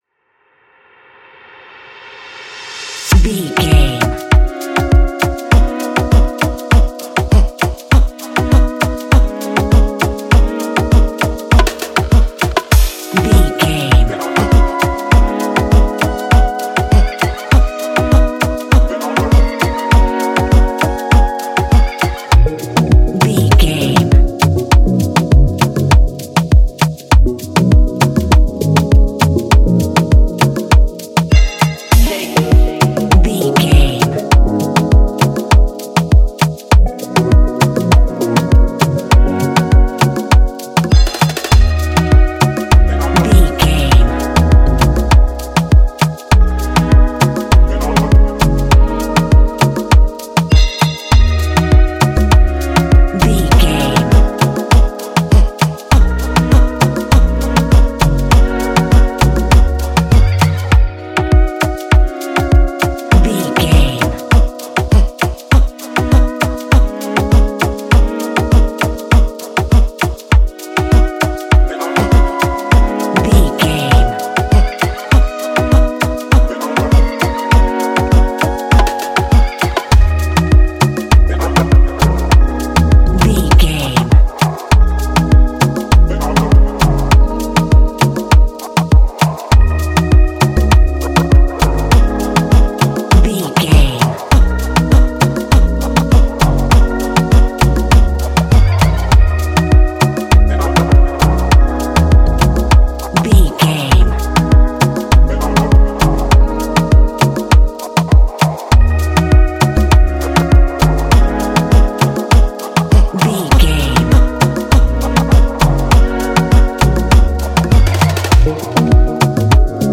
Ionian/Major
peaceful
Rhythmic